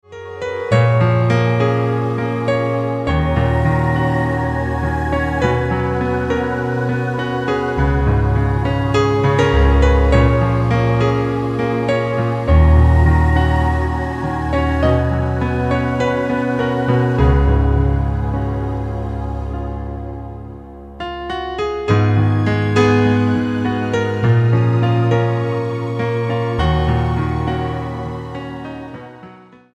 STYLE: Ambient/Meditational
Although instrumental music seldom appeals to me
create a wonderful atmosphere of peaceful tranquillity